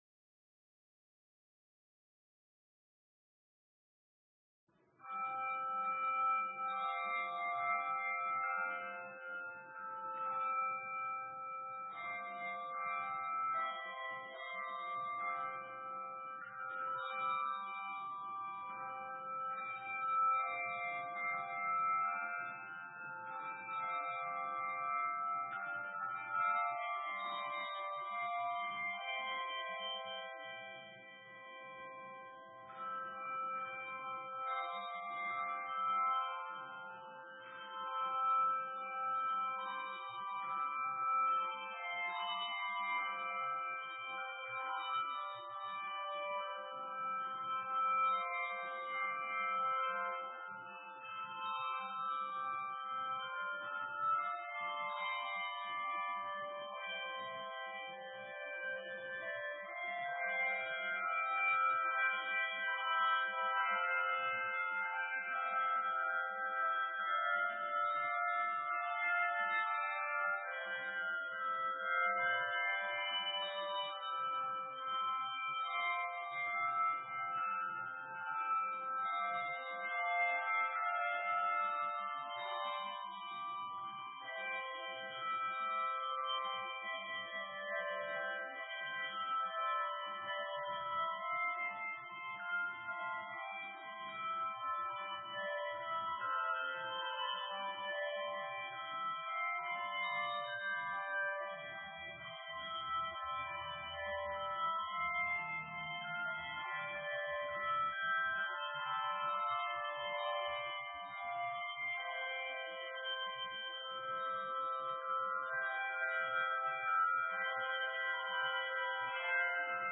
Sermon:What Does Stewardship Program Mean?
Note: The prelude begins 15 minutes into the video and at the beginning of the audio file